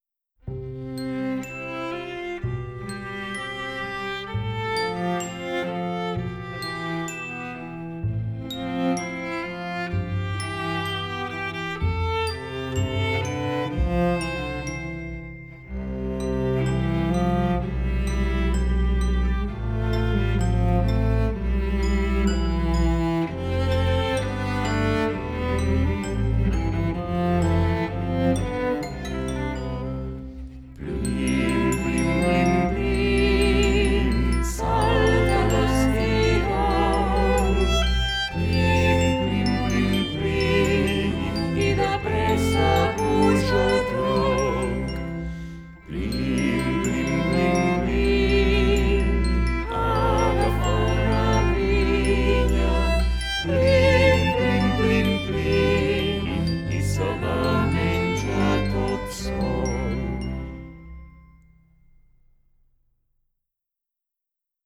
Hem anat al Palau de la Música a gaudir de Twinkle Twinkle Baby’s Star, un concert-espectacle on hem disfrutat de cançons i melodies de tradició anglesa i catalana posades al dia  amb un tractament musical modern i integrador de diferents llenguatges i estils musicals.